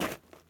SnowSteps_04.wav